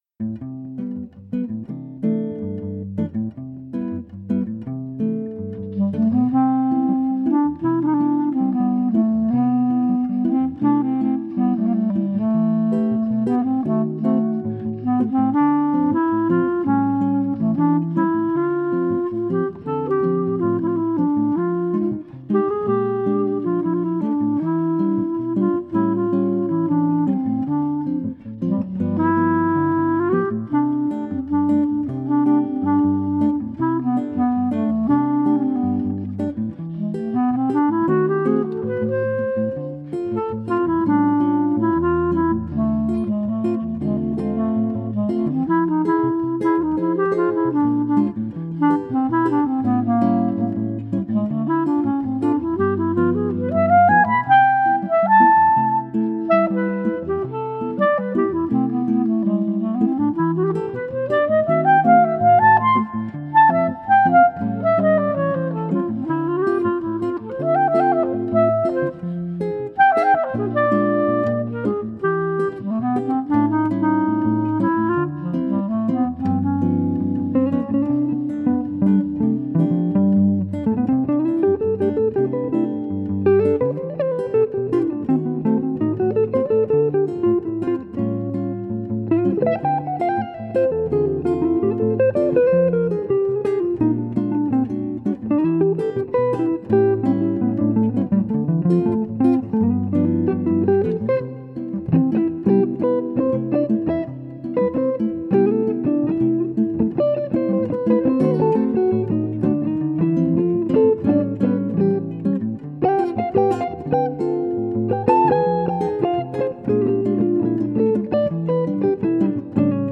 Gitarrenduo